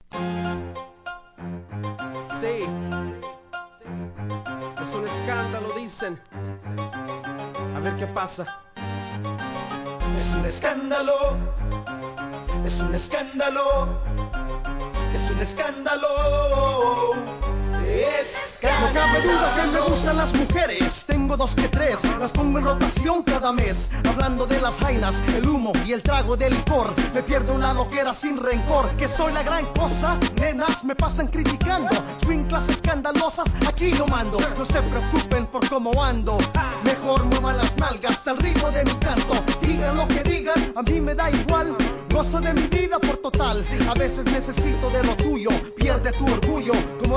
G-RAP/WEST COAST/SOUTH
スパニッシュRAP!! REGGAETON REMIXも収録!!